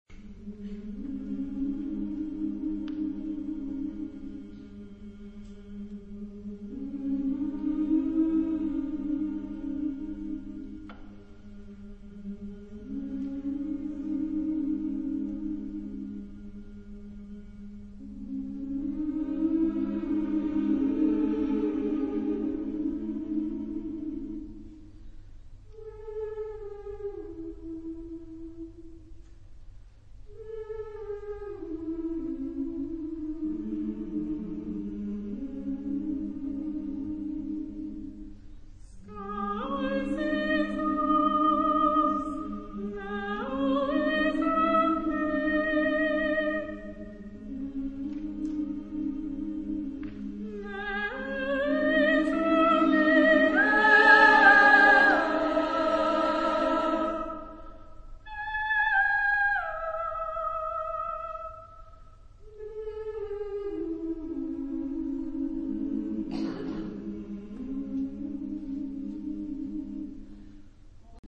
Genre-Stil-Form: Chorwerk ; Zyklus ; weltlich
Chorgattung: SSSAAA  (6 Frauenchor Stimmen )
Solisten: Sopran (1)  (1 Solist(en))
Tonart(en): frei